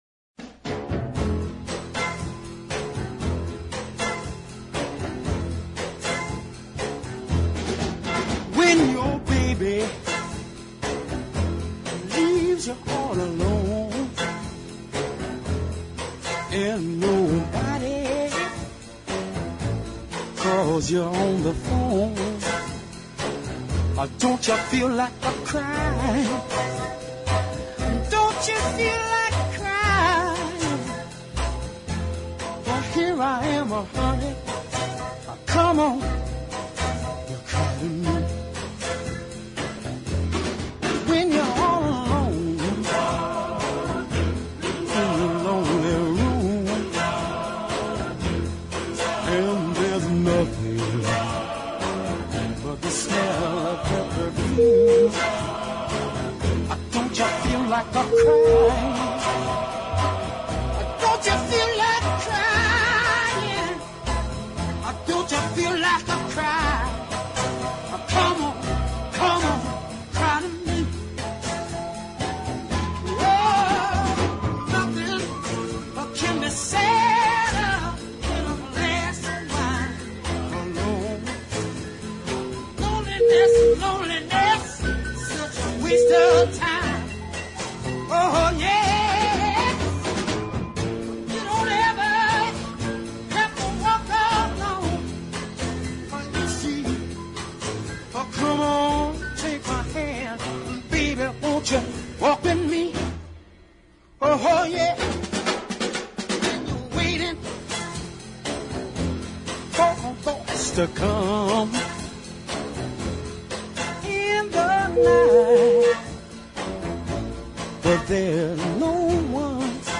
the tango-paced rhythm and overly-white back-up group